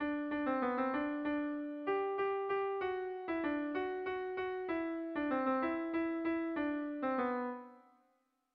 Irrizkoa
Dima < Arratia-Nerbioi < Bizkaia < Basque Country
AB